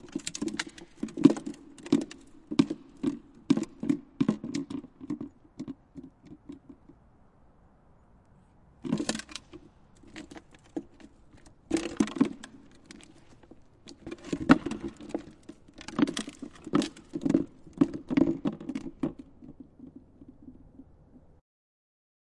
废弃的工厂金属后世界末日的回声" 大空间中的空心管
描述：记录在爱尔兰都柏林的废弃工厂。使用Zoom H6和Rode NT4。
Tag: 空间 噪声 金属 工业 回声